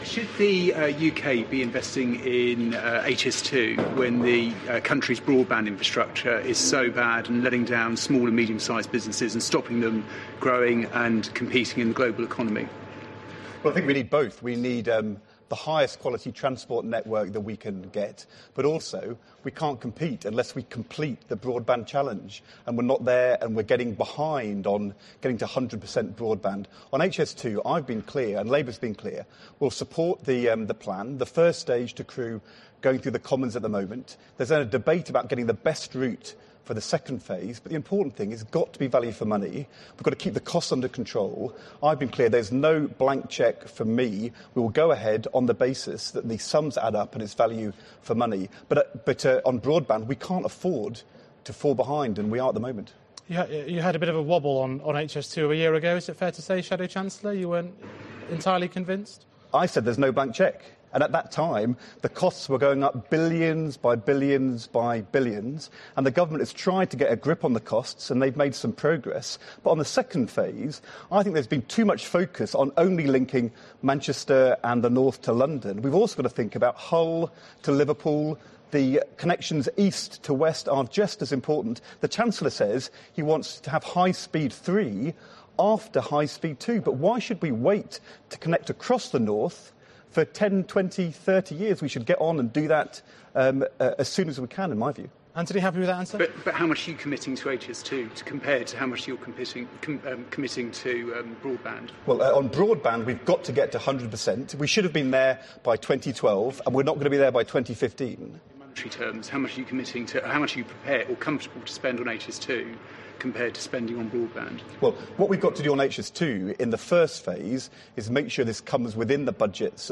Ed Balls faces questions on the deficit, mansion tax and HS2 on Sky News Q&A
Listen in full to Ed Balls's appearance on Sky News's Ask The Chancellors. The Shadow Chancellor faced questions on the deficit, HS2 and Labour's proposed mansion tax. 23 March 2015.